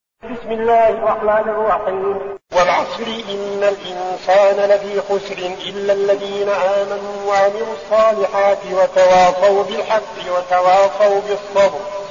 المكان: المسجد النبوي الشيخ: فضيلة الشيخ عبدالعزيز بن صالح فضيلة الشيخ عبدالعزيز بن صالح العصر